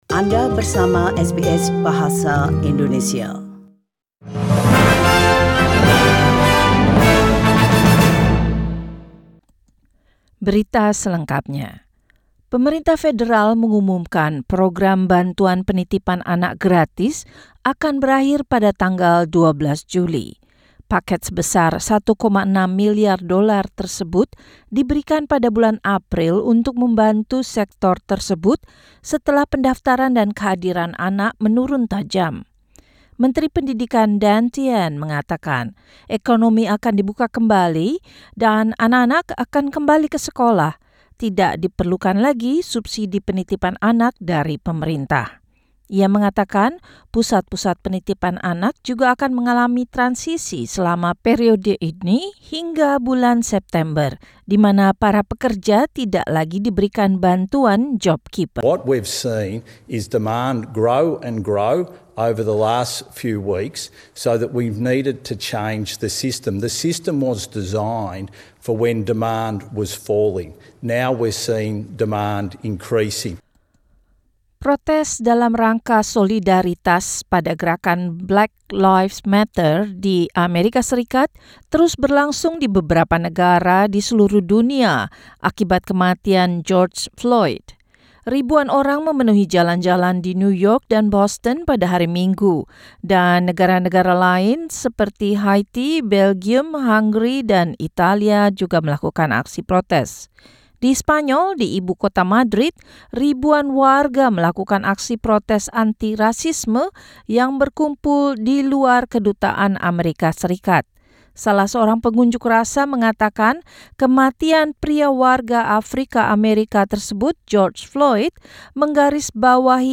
SBS Radio News in Indonesian 8 June 2020